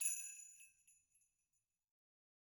Sleighbells_Hit_v1_rr1_Mid.wav